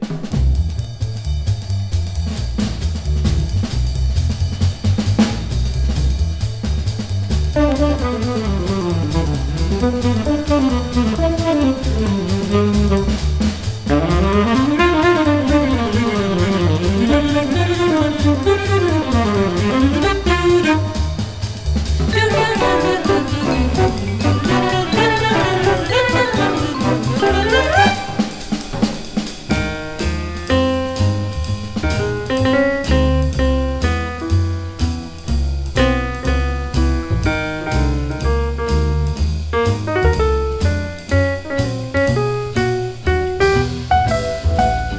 スウィング感と洒落たアレンジも魅力たっぷり。
Jazz, Stage & Screen　USA　12inchレコード　33rpm　Mono